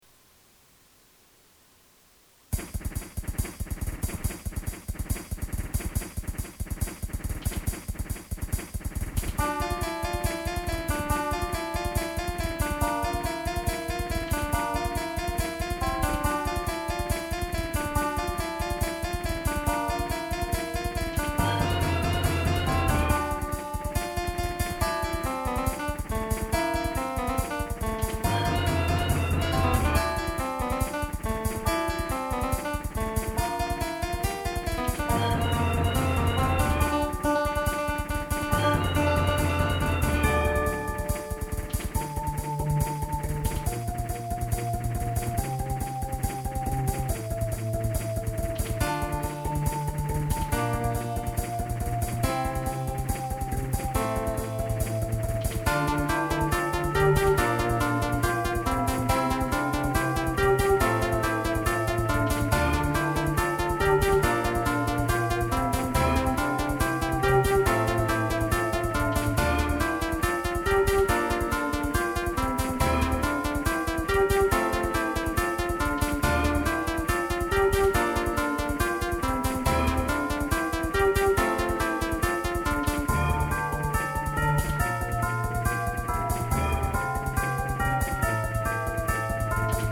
Made with my keyboard. Boss music.